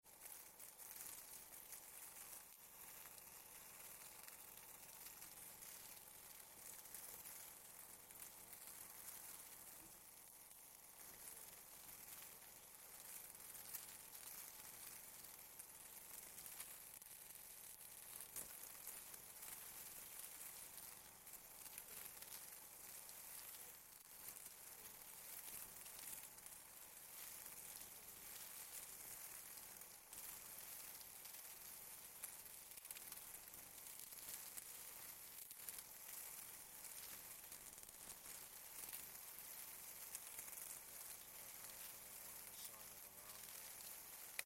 На этой странице собраны звуки термитов – от тихого шуршания до характерного постукивания.
Шум крыльев роя термитов